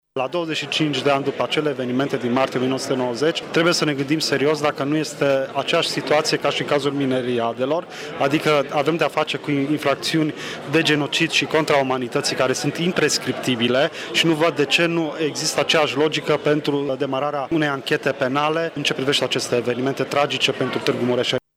Prezent astăzi la Tîrgu-Mureș, la conferinţa ”Identitate, etnicitate, etnocentrism, nationalism, în contextul actual al Uniunii Europene”, judecătorul Horațius Dumbravă, membru al CSM, susține că dosarele de genocid nu sunt prescriptibile.